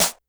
snr_22.wav